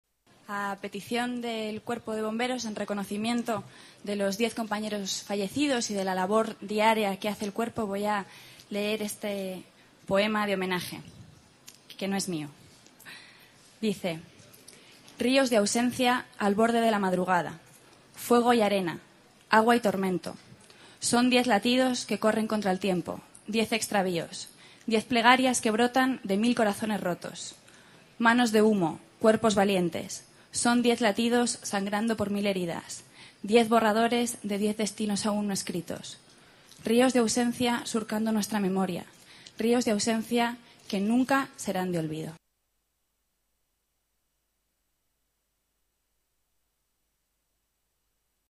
Maestre y Barbero en el homenaje a los bomberos fallecidos en Almacenes Arias - Ayuntamiento de Madrid
Nueva ventana:Maestre lee un poema en el homenaje a los bomberos